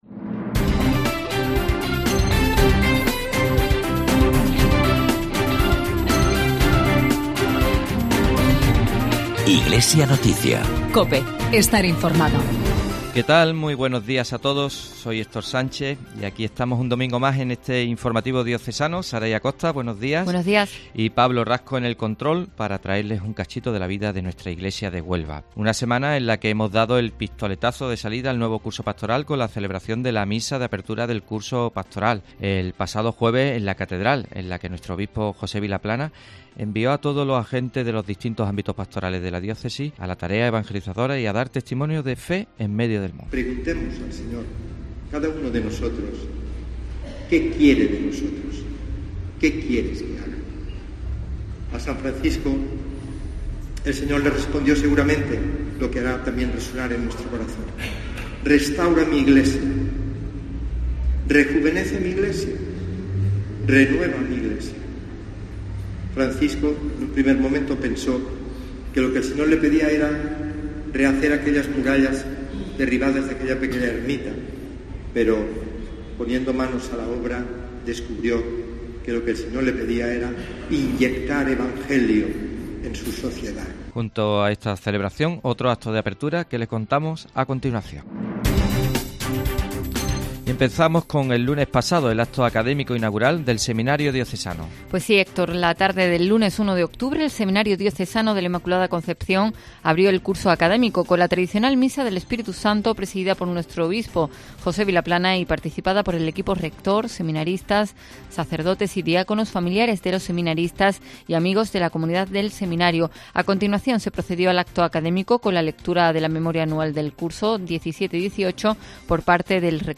El informativo diocesano de esta semana nos acerca a todos los actos de apertura que se han sucedido en estos días: el Seminario Diocesano, la Universidad, la Missio... y otras noticias más.